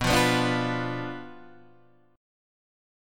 Bdim chord {x 2 3 4 3 1} chord